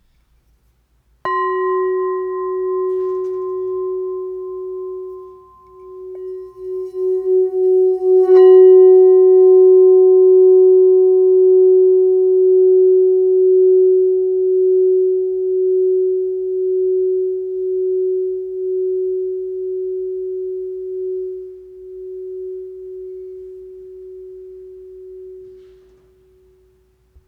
F Note 6.5″ Singing Bowl